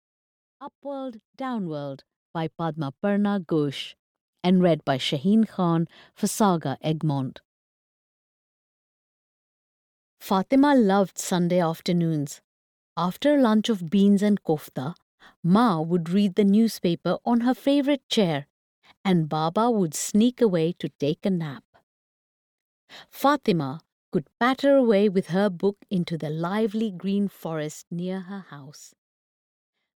Audio knihaUp World, Down World (EN)
Ukázka z knihy